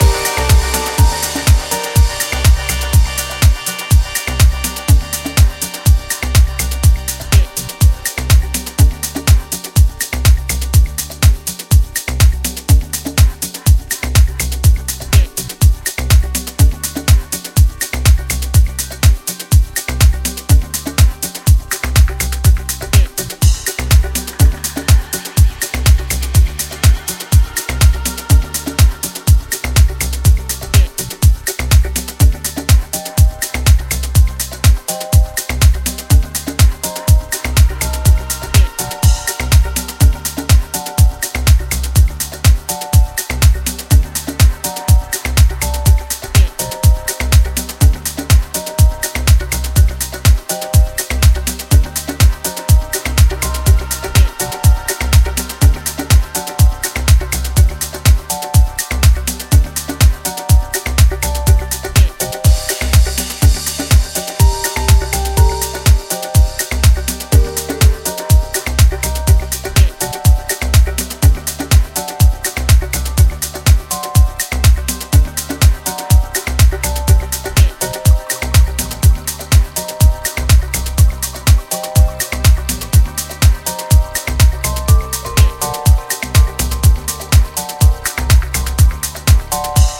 中東のメロディックなフレーバーにスパイスを効かせたパーカッシブなトラックで、メロディック・ハウス＆テクノを展開。
ジャンル(スタイル) HOUSE / TECHNO